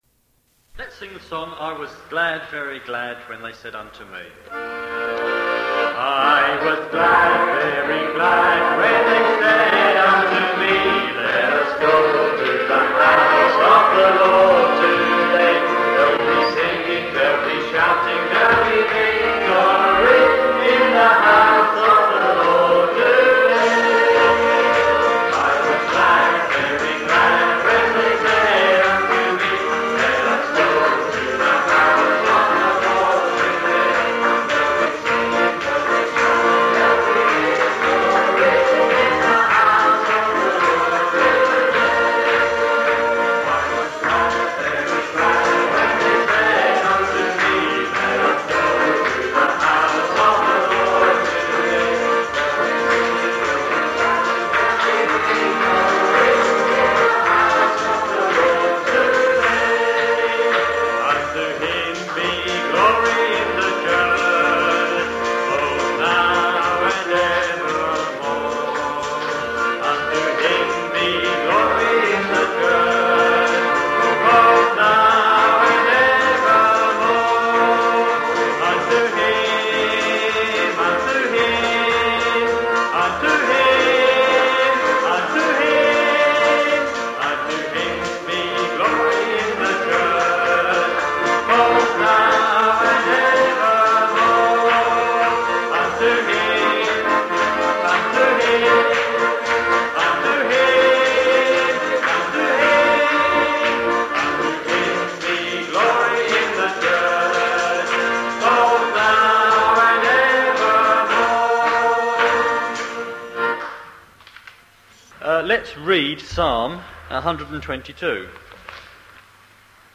The Church at Gun Hill - Online Bible Study